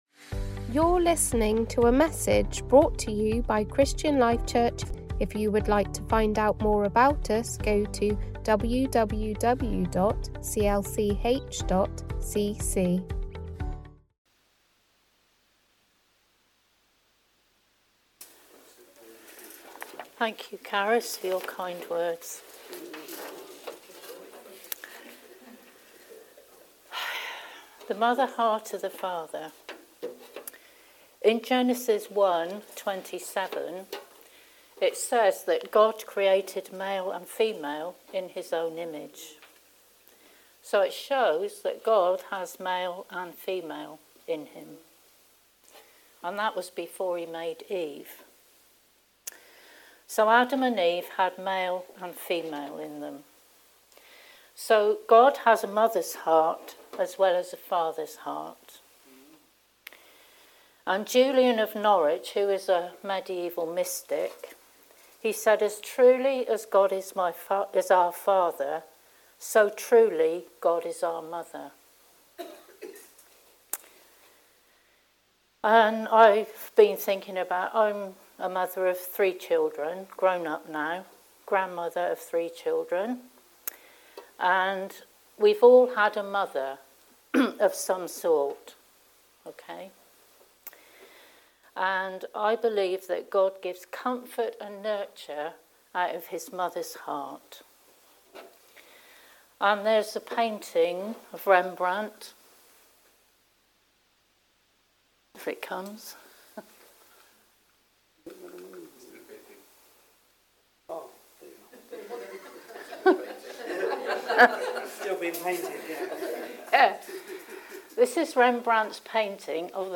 Location: Ross Sunday